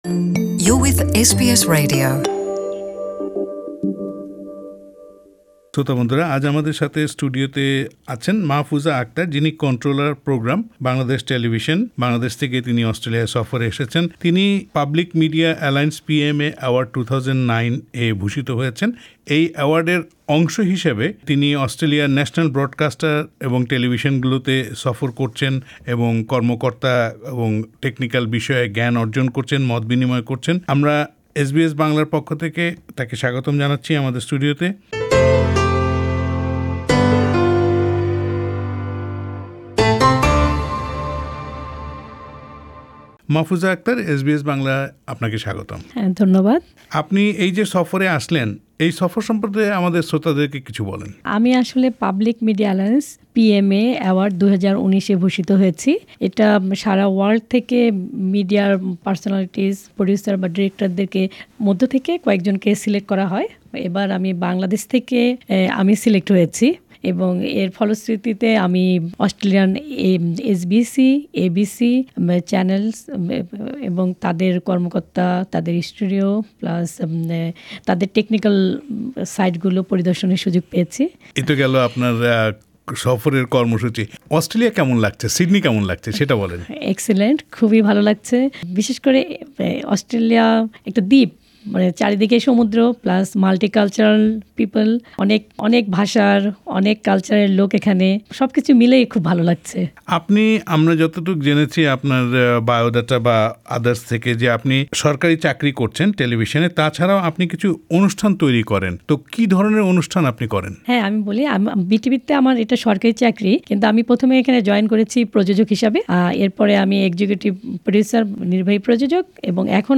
সিডনিতে এসবিএস এর প্রধান কার্যালয় পরিদর্শনকালে এসবিএস বাংলার সঙ্গে কথা বলেন তিনি।
সাক্ষাৎকারটি বাংলায় শুনতে উপরের অডিও প্লেয়ারটিতে ক্লিক করুন।